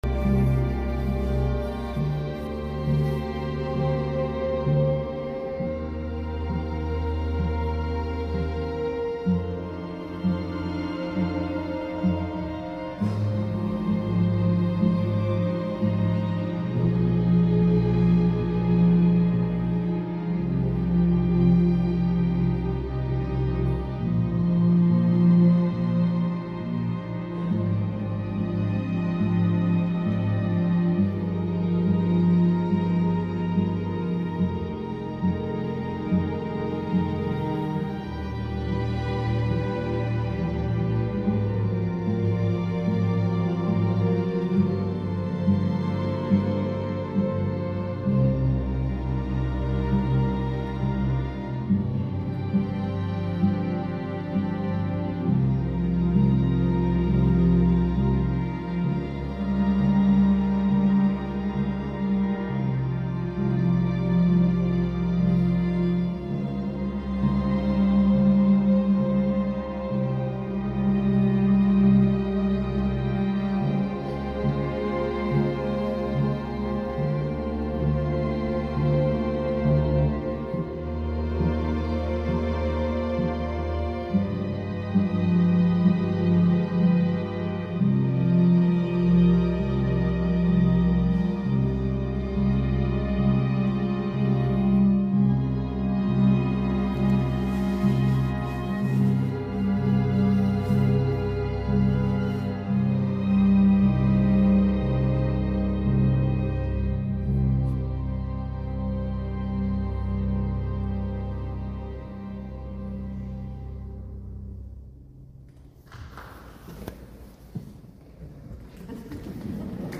Friedenskonzert 2025 in der Christians Kirche in Ottensen, Altona, Hamburg.
Töne von Marschmusik des Streichorchesters brechen gleich wieder zusammen, wechseln in Differenz, in Demokratie und Vielfalt.
Es ist Vielfalt und Demokratie im Raum und in der Zeit – mit Kontroversen und Harmonien, mit Obacht und Respekt.
Vom Konzert in der Christians Kirche 2025 gibt es hier zwei Audio-Mitschnitte als MP3.